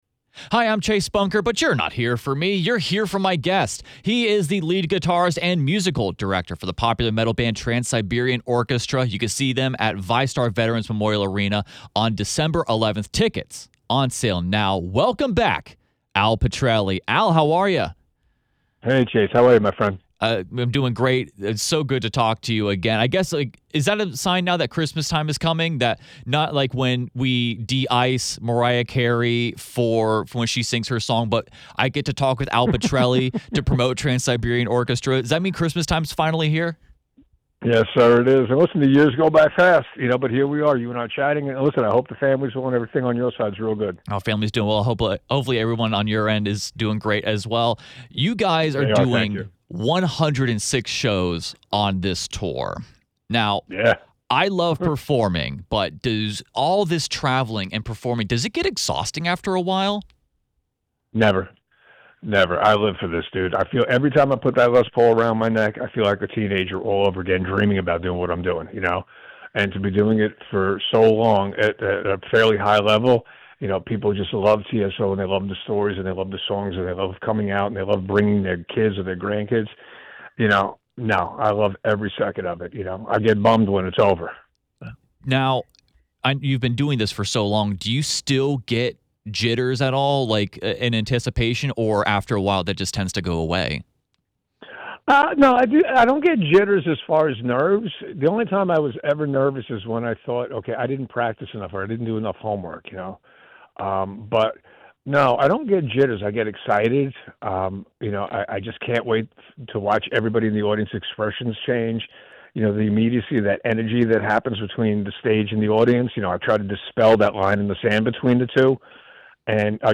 ▶ Listen to Jacksonville’s Morning News Interviews